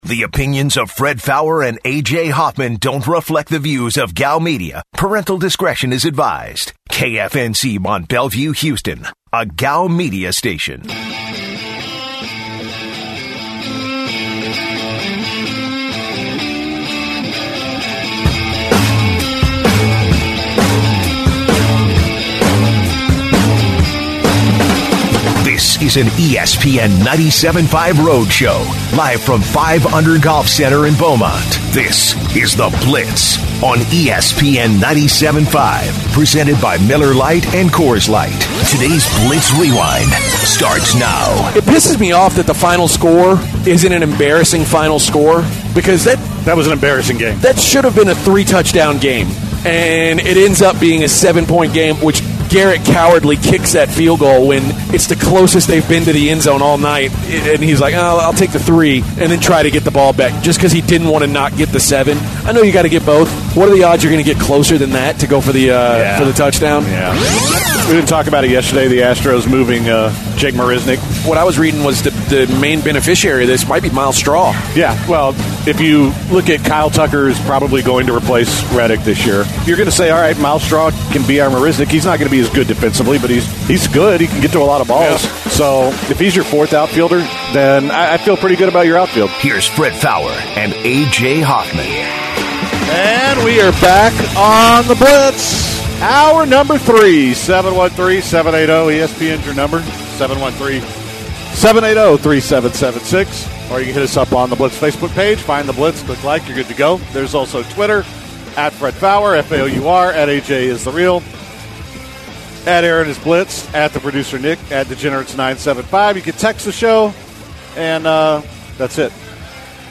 broadcast live from 5 Under Golf in Beaumont! They talk about a professor at LSU that thinks vampires exist and live all over the country;